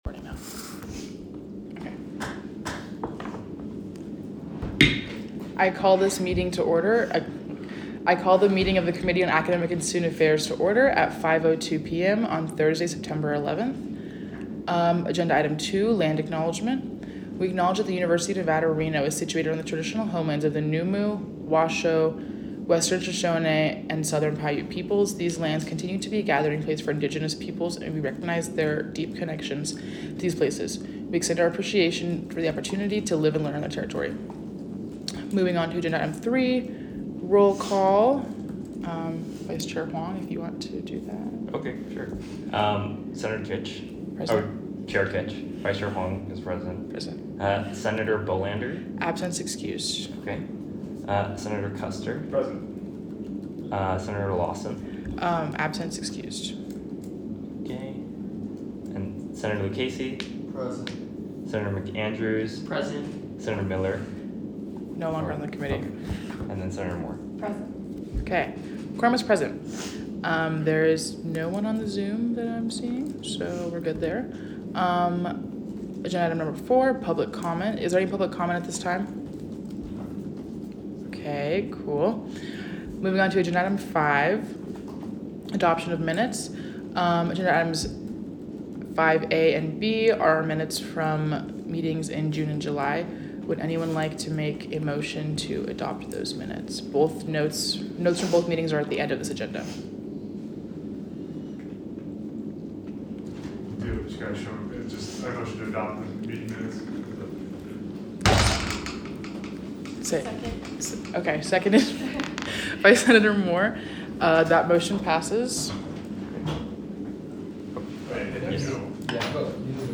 Location : Frankie Sue Del Papa President's Conference Room
Audio Minutes